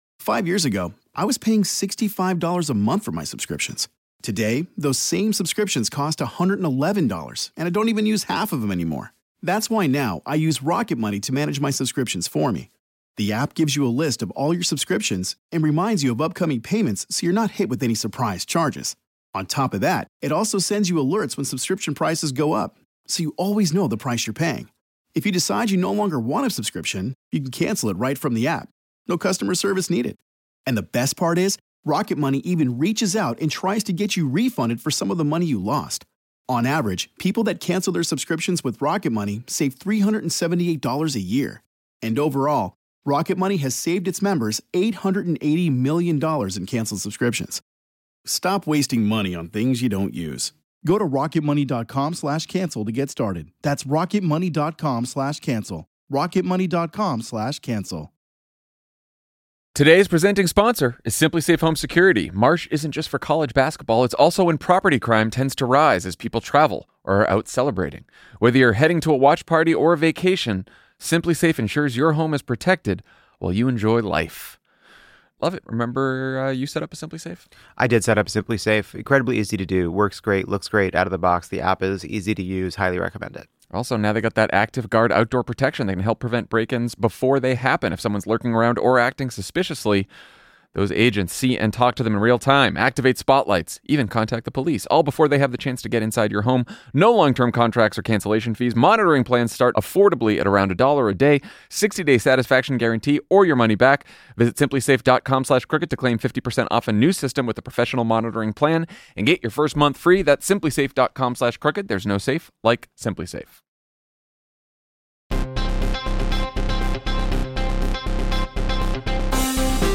More American institutions cave to Trump's pressure campaigns, and the administration presses on with its effort to use the Alien Enemies Act to deport immigrants without so much as a hearing. Jon, Lovett, and Tommy break down all the latest developments and compare notes from a weekend spent in the field with Democratic campaigners. Then, Kentucky Governor Andy Beshear sits down with Jon to talk about how we can win in red states.